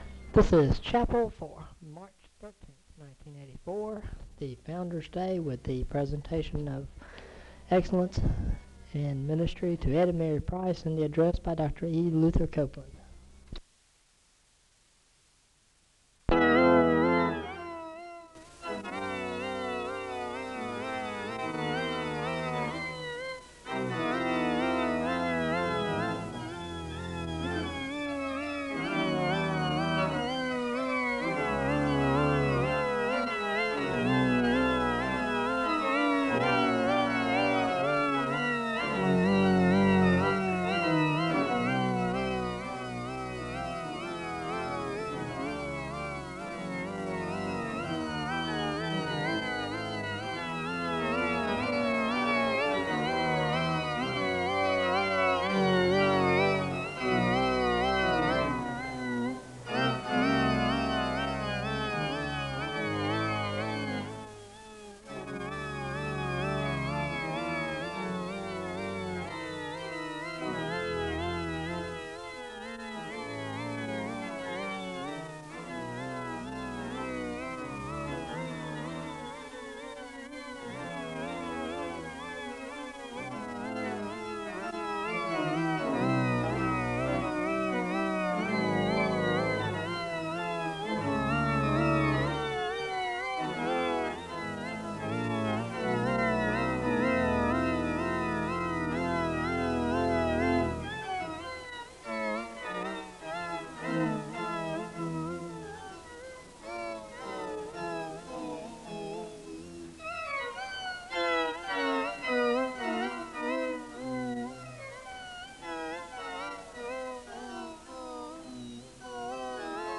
The service begins with organ music, and the choir sings a song of worship (0:00:00-0:11:06).
The choir sings the anthem (0:29:02-0:34:33).
The choir sings a song of worship (0:56:38-1:00:39). The service ends with a word of prayer (1:00:40-1:01:15).
Religious education